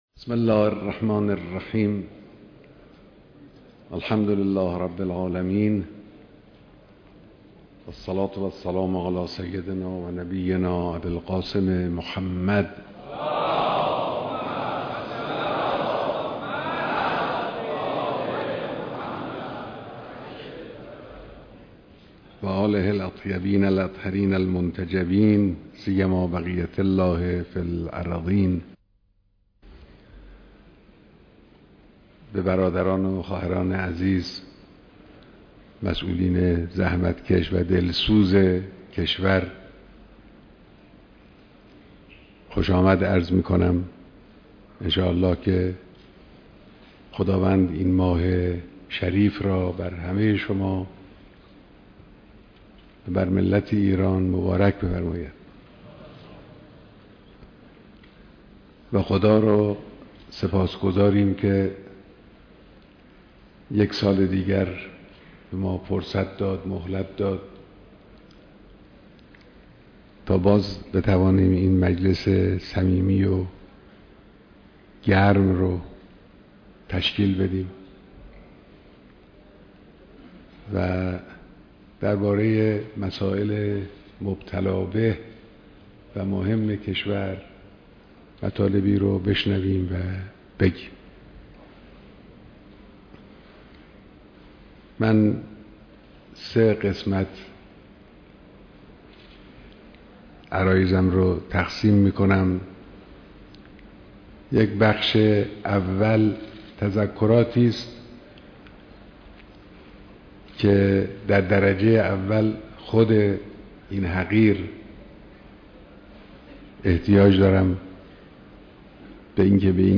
بيانات در ديدار مسئولان نظام جمهورى اسلامى ايران